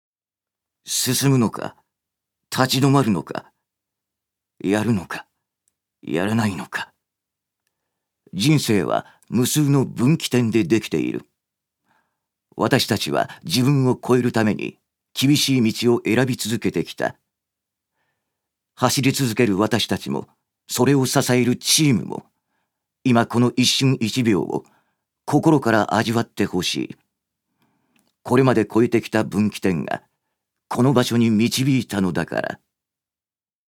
預かり：男性
ナレーション３